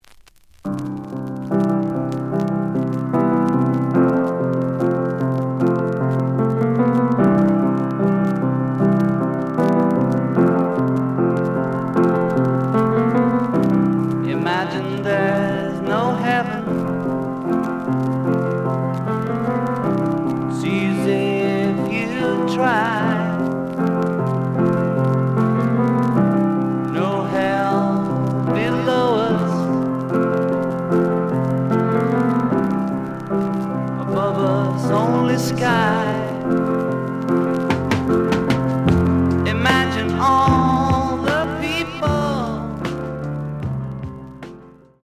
The audio sample is recorded from the actual item.
●Genre: Rock / Pop
Slight noise on A side.